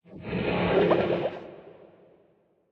Minecraft Version Minecraft Version 1.21.5 Latest Release | Latest Snapshot 1.21.5 / assets / minecraft / sounds / mob / horse / skeleton / water / idle5.ogg Compare With Compare With Latest Release | Latest Snapshot